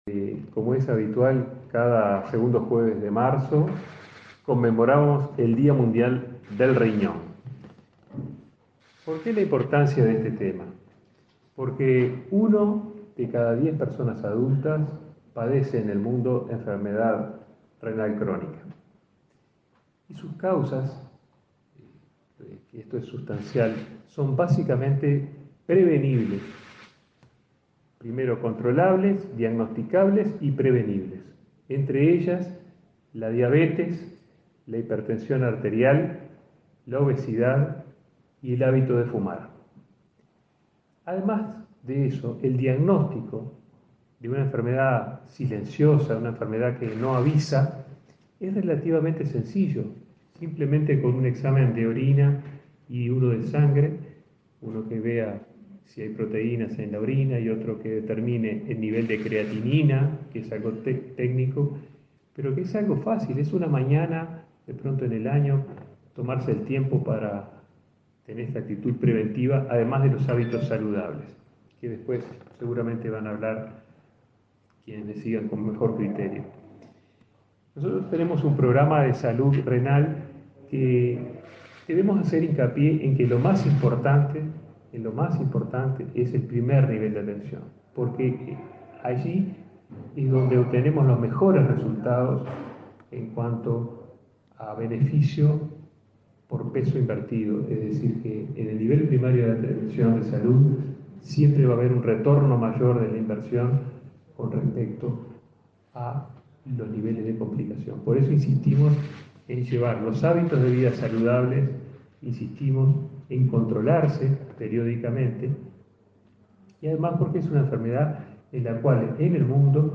En el Día Mundial del Riñón, se realizó un acto en el Fondo Nacional de Recursos (FNR), en el que participaron el ministro de Salud, Daniel Salinas;